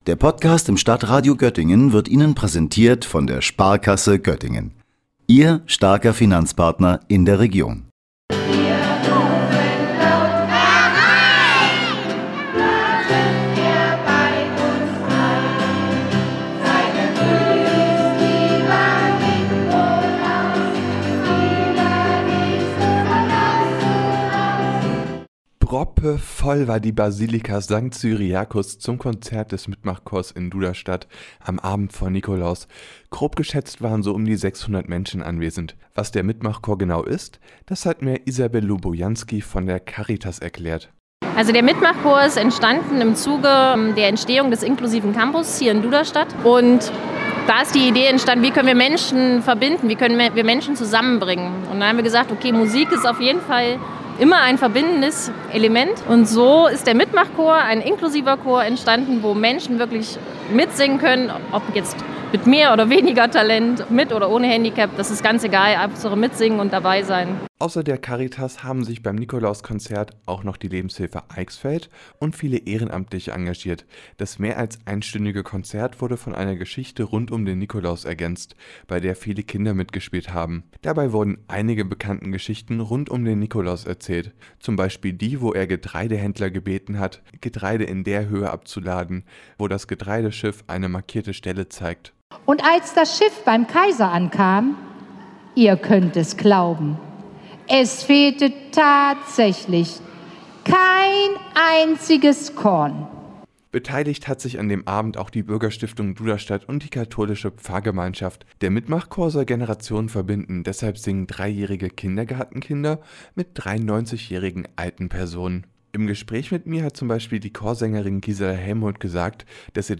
MitMachChor der Caritas: Nikolauskonzert sorgt für volle Basilika in Duderstadt